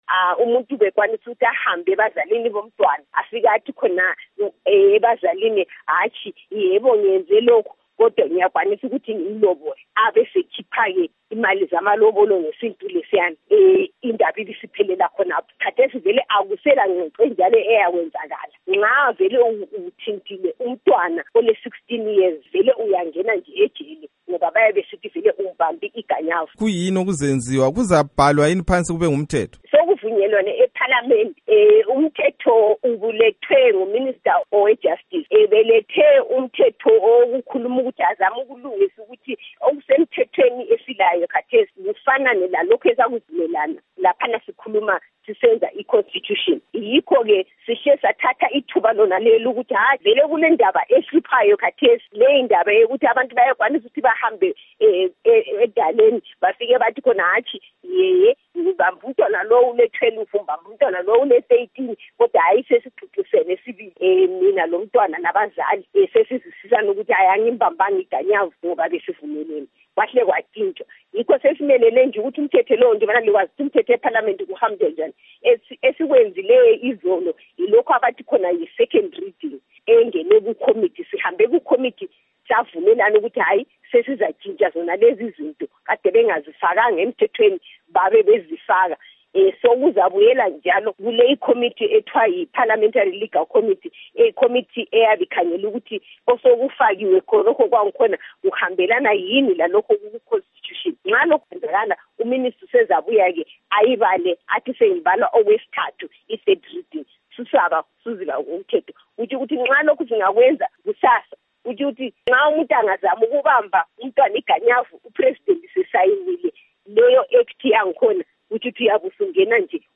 Ingxoxo loNkosikazi Priscilla Misihairambwi Mushonga